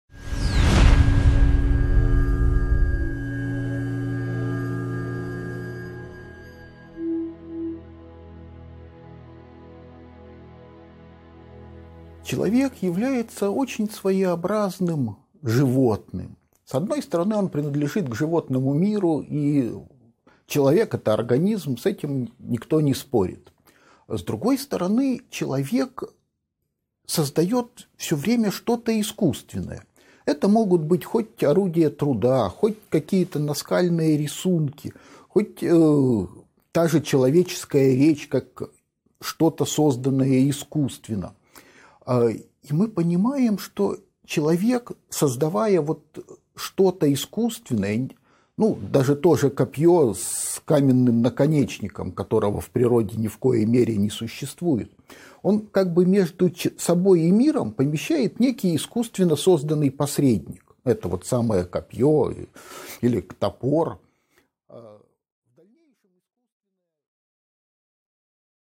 Аудиокнига 1.7 От мифа к Логосу | Библиотека аудиокниг